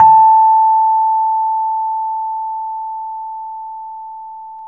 RHODES-A4.wav